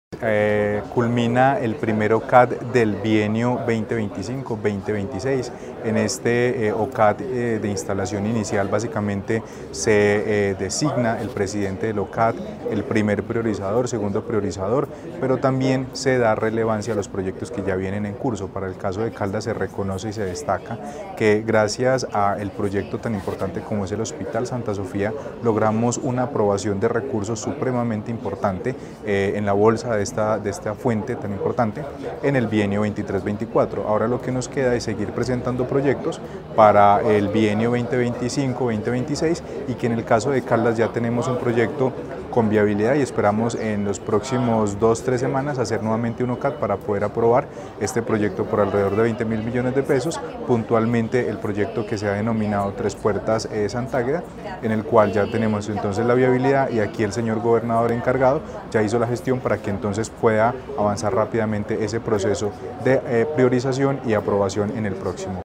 Carlos Anderson García, secretario de Planeación de Caldas.